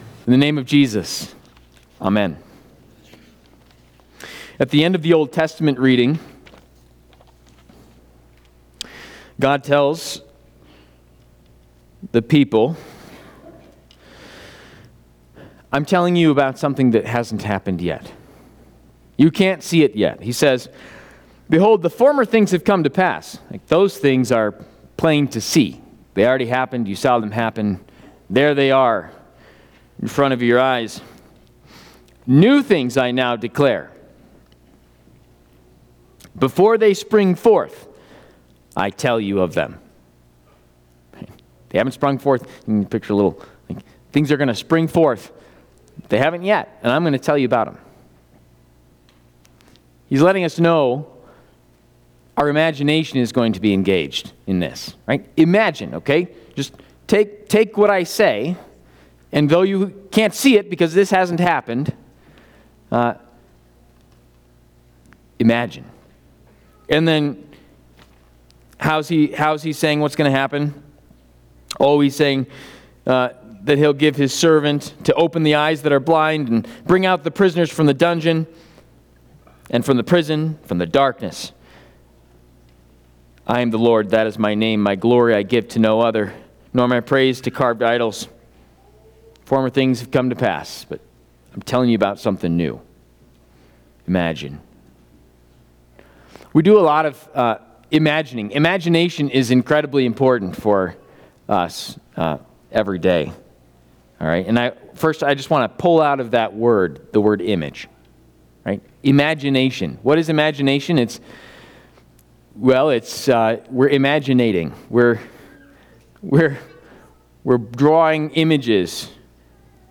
Baptism Of Our Lord